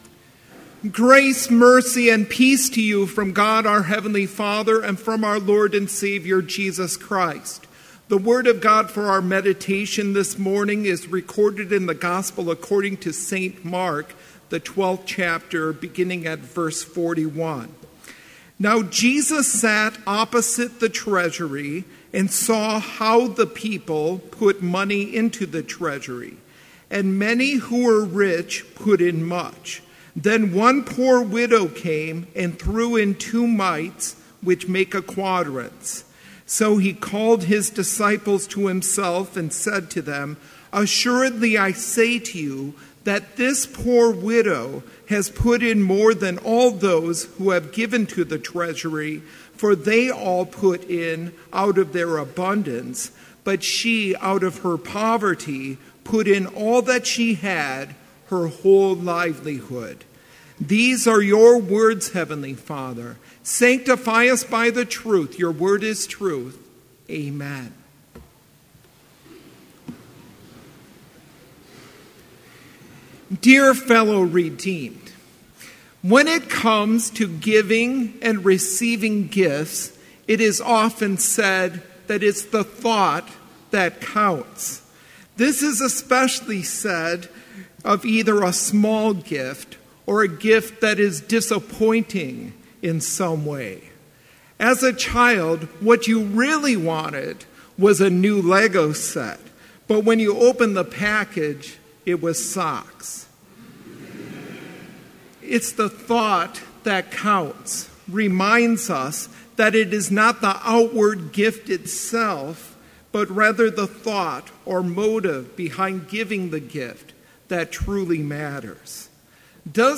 Complete service audio for Chapel - September 26, 2017